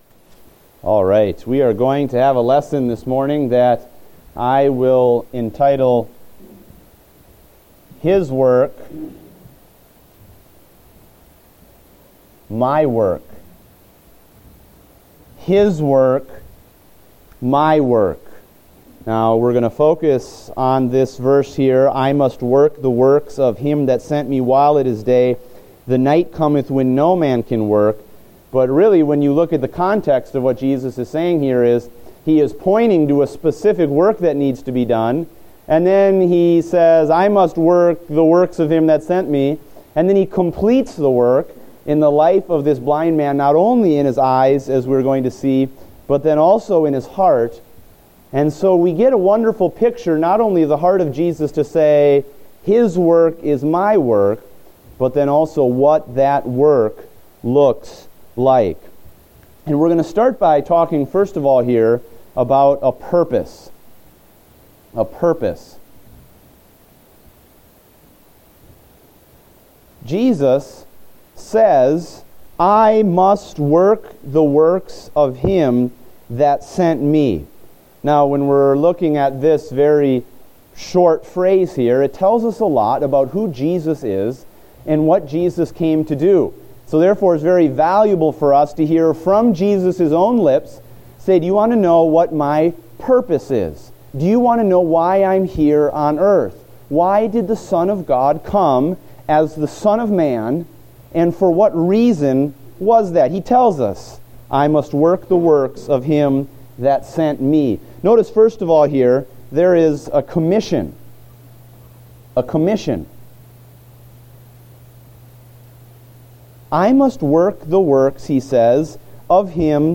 Date: January 17, 2016 (Adult Sunday School)